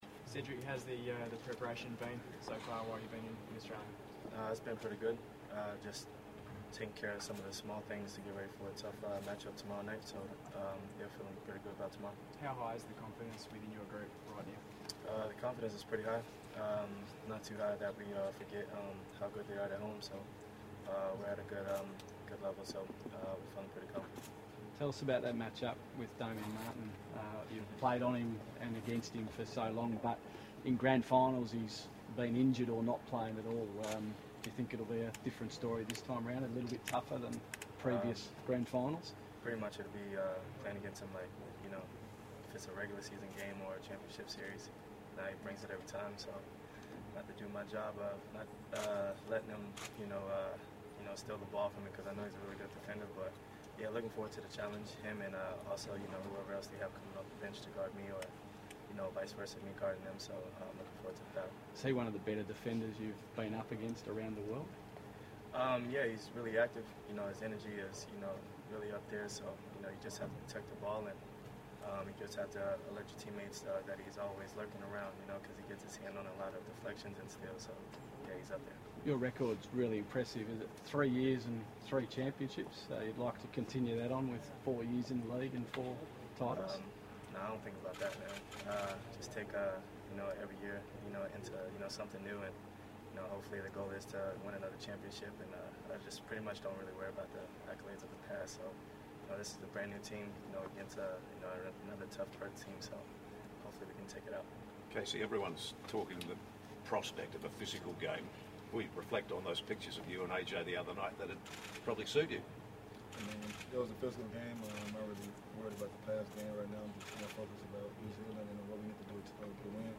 Pre-Grand Final Press Conference